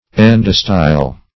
endostyle.mp3